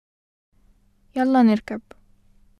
[ yalla nerkab. ]